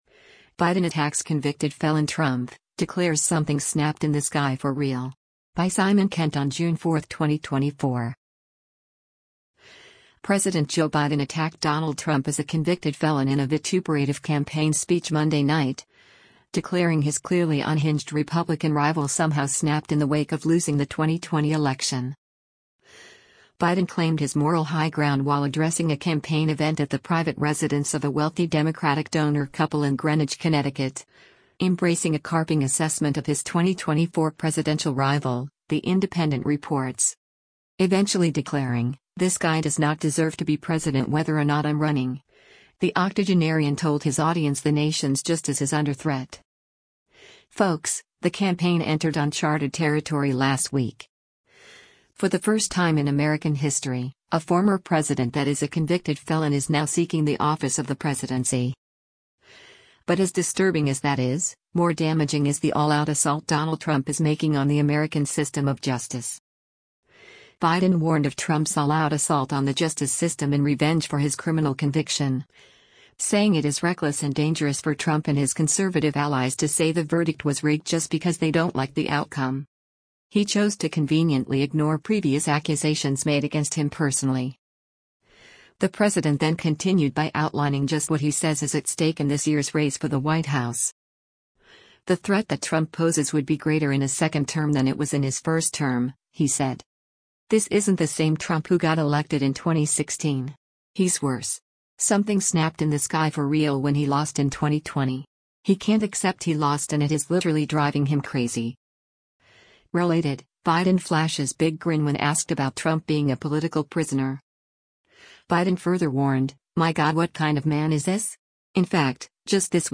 President Joe Biden attacked Donald Trump as a “convicted felon” in a vituperative campaign speech Monday night, declaring his “clearly unhinged” Republican rival somehow “snapped” in the wake of losing the 2020 election.
Biden claimed his moral high ground while addressing a campaign event at the private residence of a wealthy Democratic donor couple in Greenwich, Connecticut, embracing a carping assessment of his 2024 presidential rival, the Independent reports.